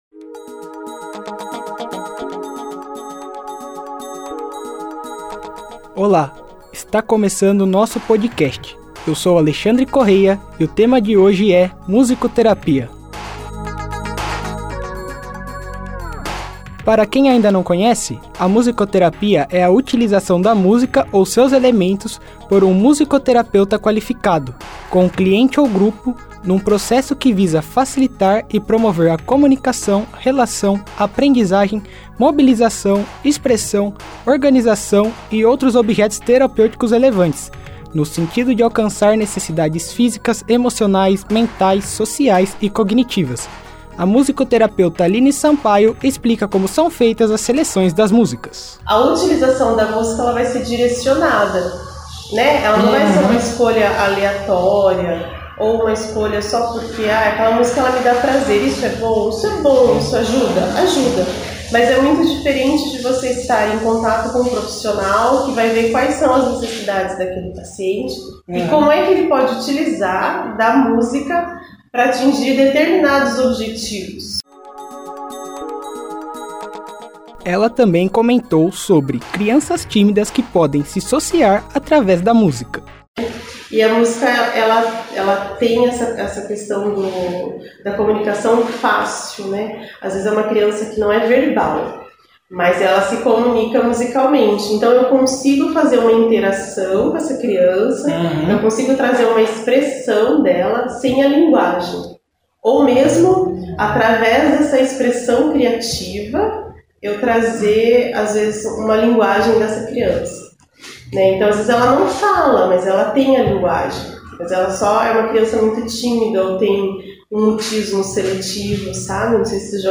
Podcast sobre musicoterapia com uma profissional da área